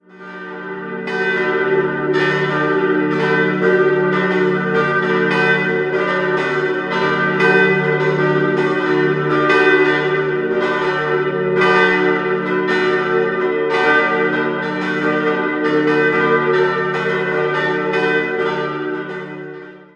4-stimmiges Salve-Regina-Geläute: d'-fis'-a'-h' Die kleine Glocke wurde 1936, die zweitkleinste 1950 von Hamm in Regensburg gegossen.